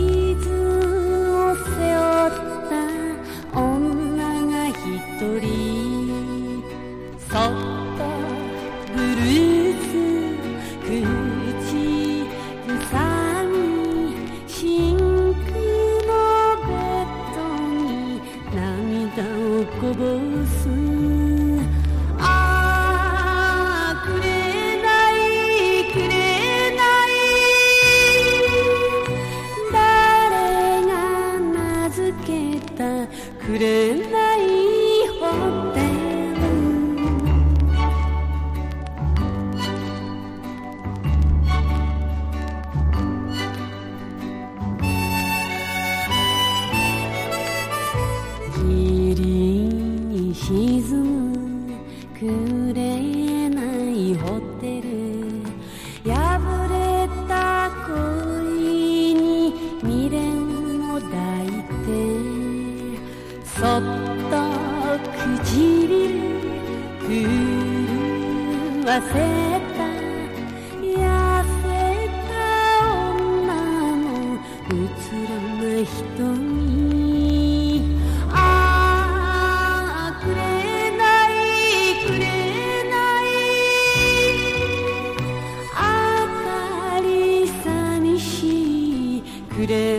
ミニマムな伴奏で歌い上げる